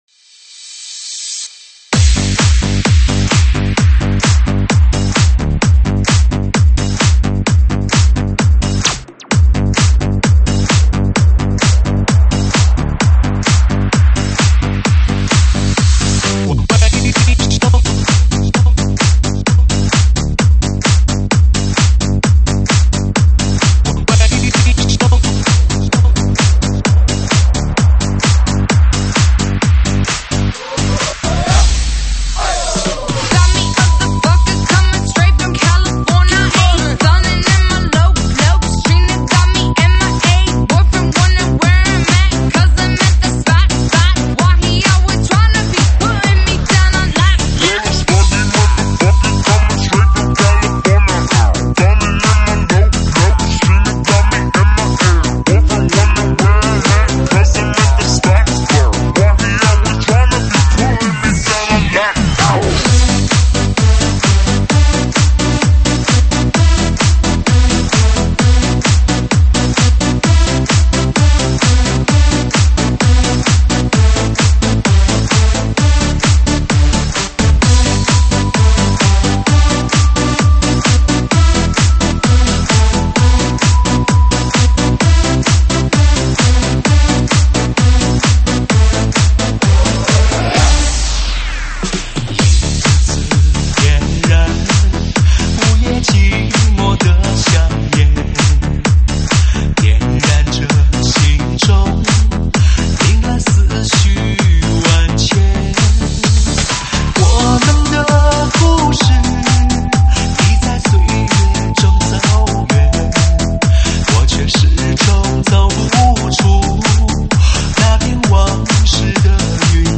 收录于(现场串烧)